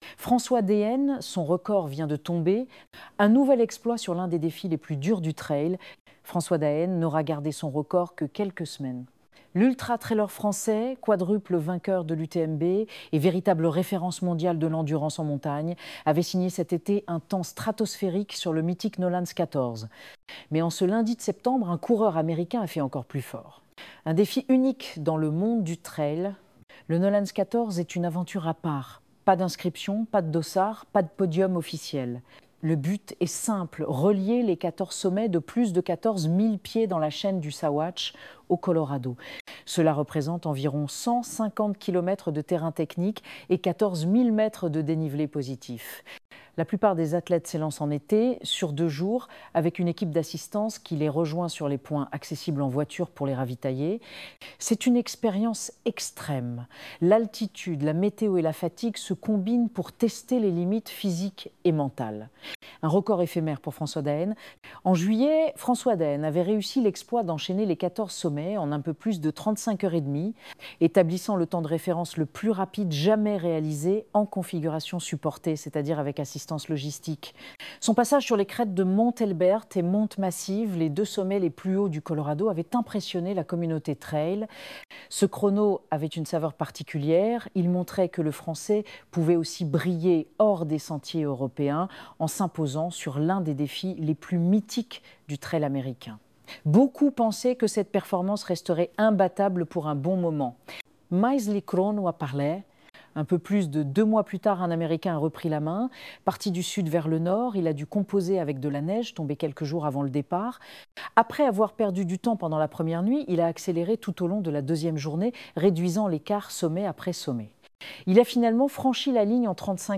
Ecouter cet article sur François d’Haene